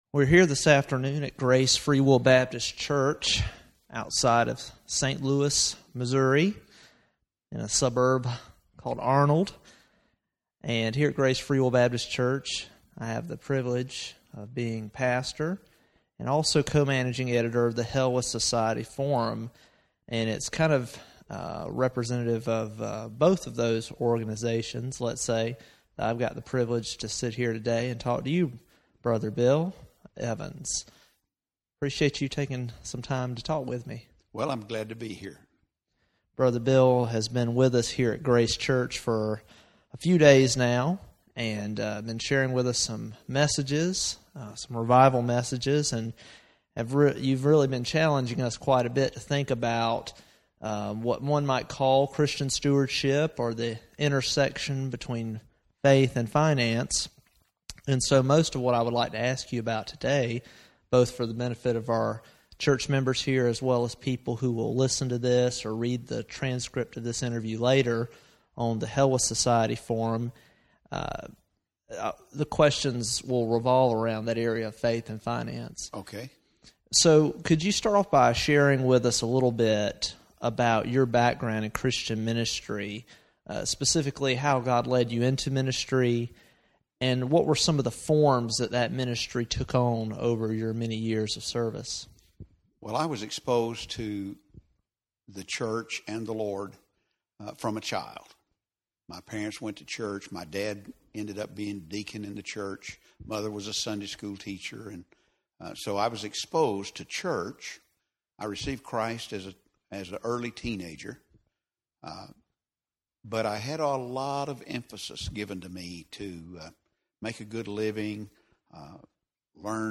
Faith & Finance: An Interview